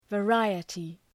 Προφορά
{və’raıətı}